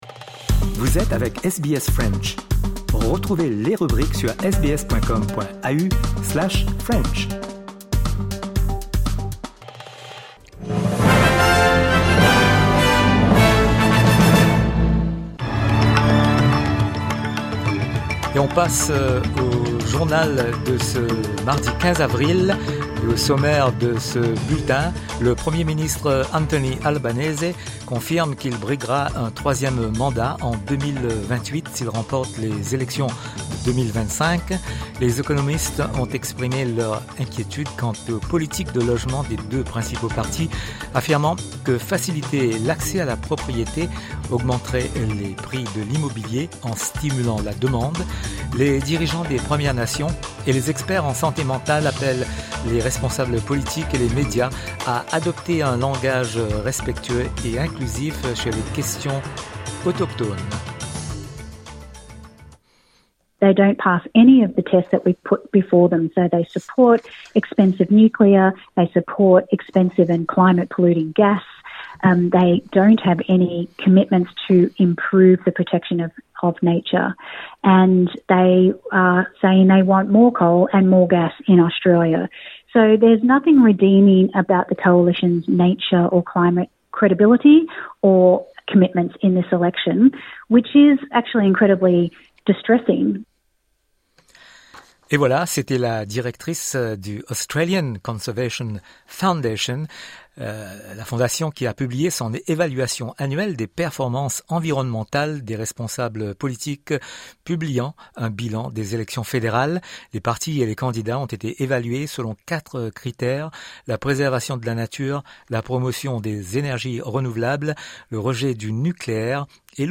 SBS French News